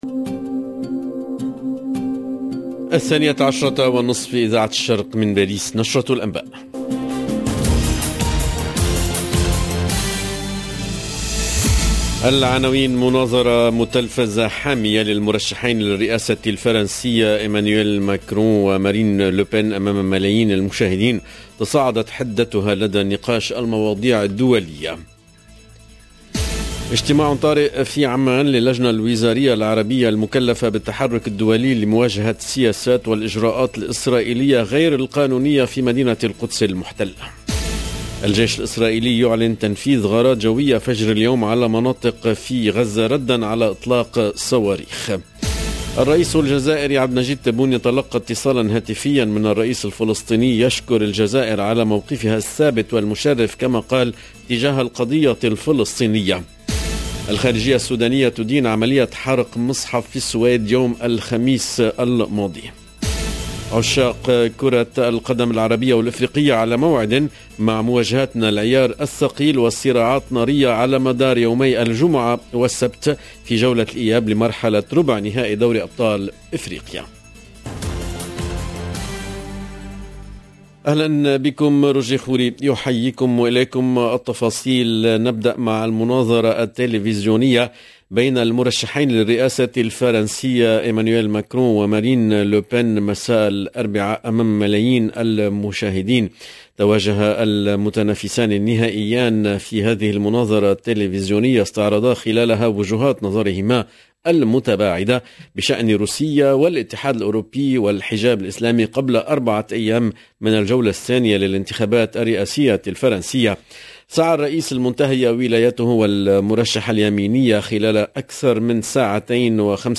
LE JOURNAL DE MIDI EN LANGUE ARABE DU 21/04/22